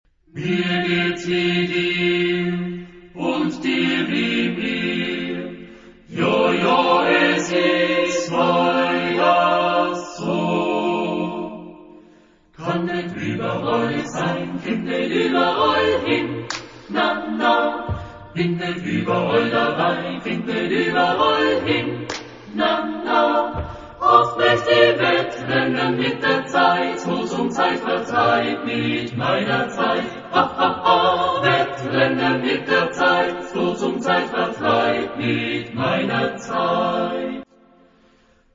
Genre-Style-Form: Secular ; Popular
Mood of the piece: descriptive
Type of Choir: SAAB OR SATB  (4 mixed voices )
Tonality: B flat major
junge alpenländische Chorlieder & Poesien